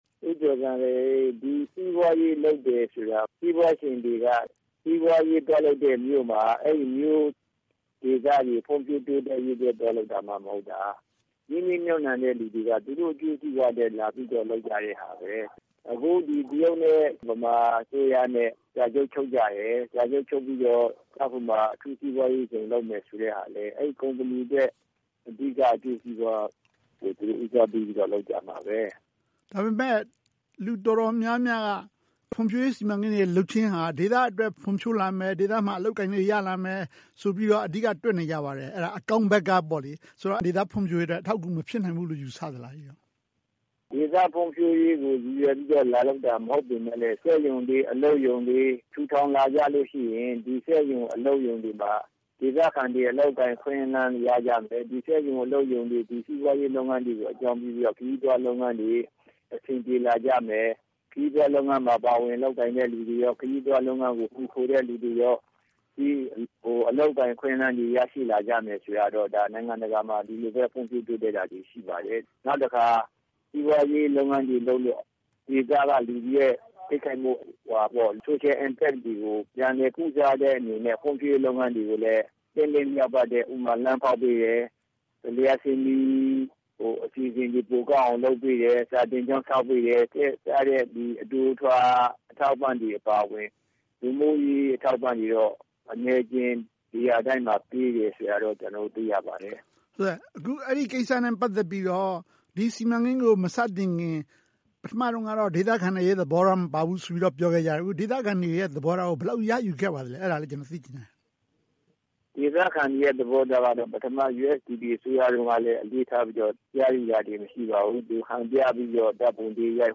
ဆွေးနွေးသုံးသပ်ထားပါတယ်